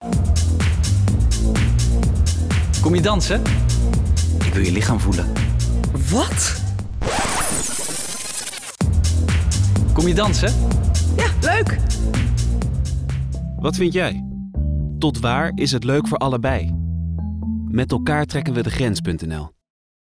Je hoort een bandje dat terugspoelt.